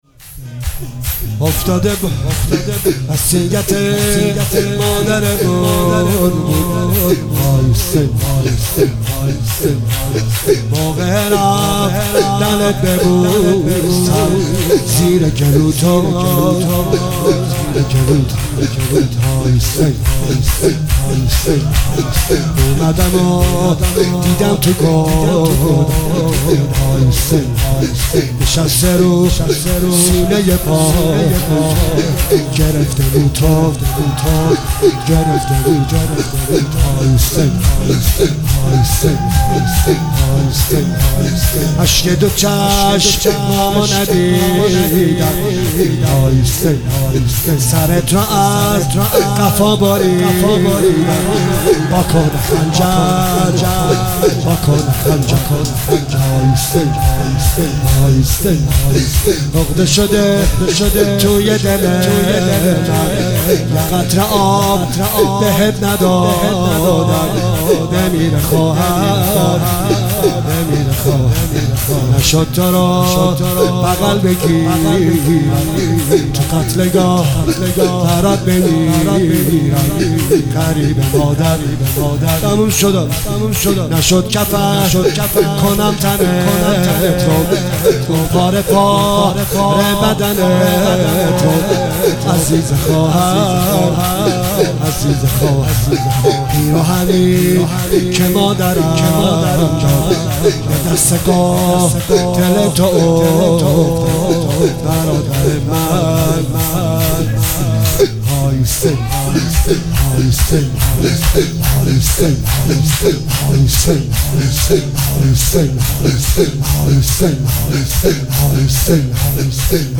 مداحی شور لطمه زنی